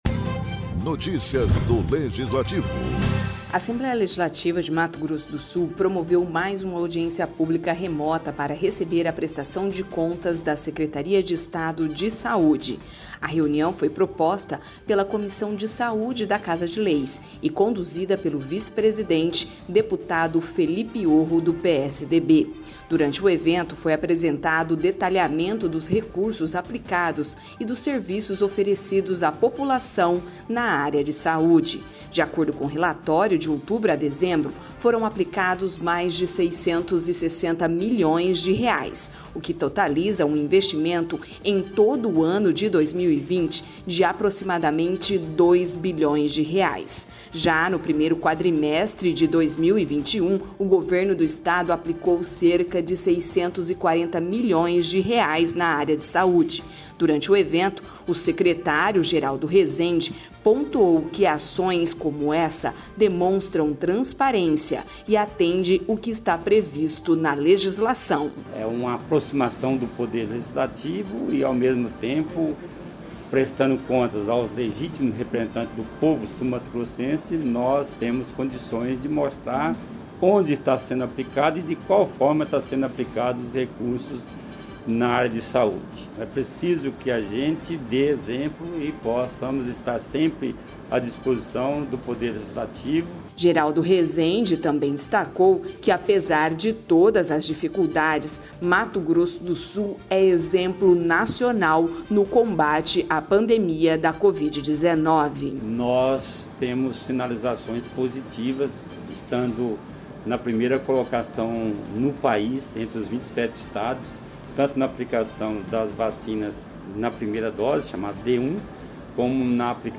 A Assembleia Legislativa de Mato Grosso do Sul (ALEMS) promoveu mais uma audiência pública remota para receber a prestação de contas da Secretaria de Estado de Saúde (SES).